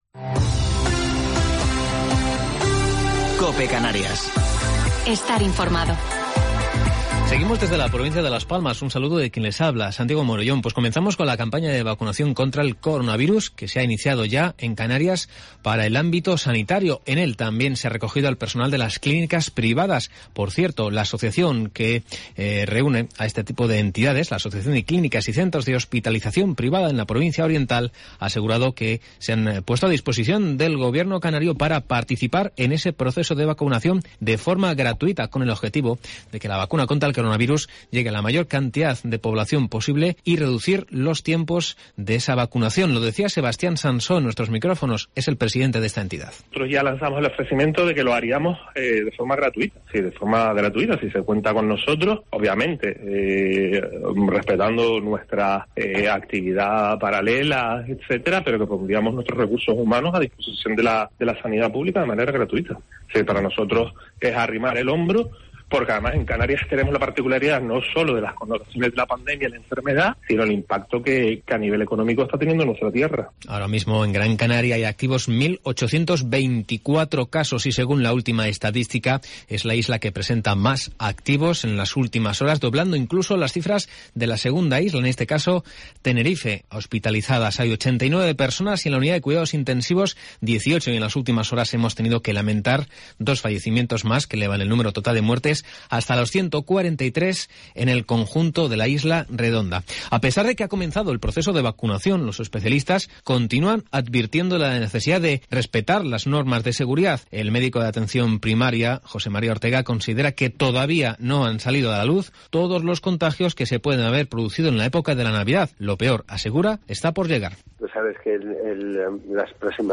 Informativo local 12 de Enero del 2021